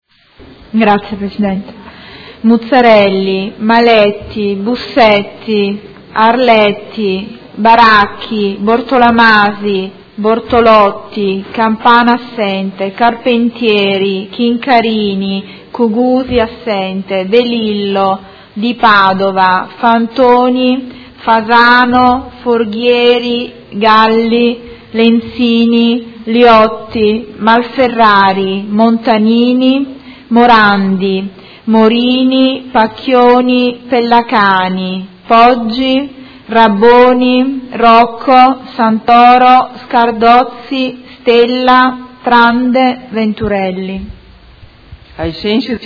Segretario Generale — Sito Audio Consiglio Comunale
Appello